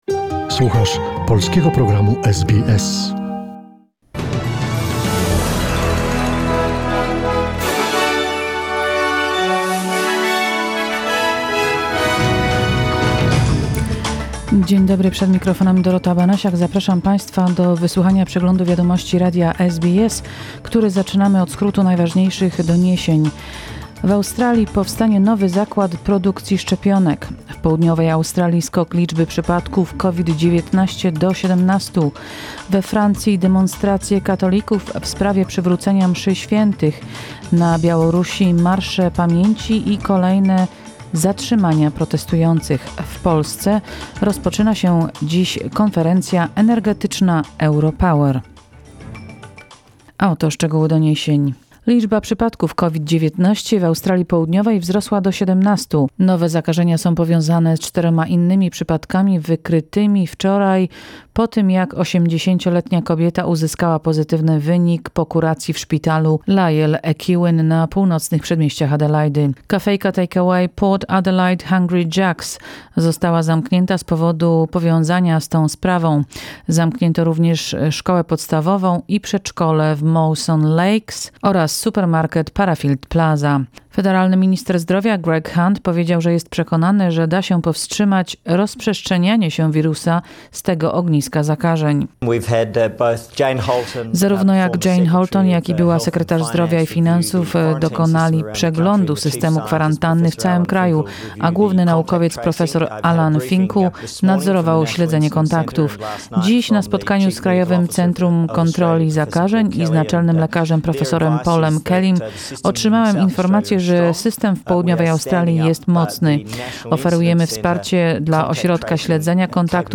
SBS News, 16 November 2020